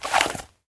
pistol_draw.wav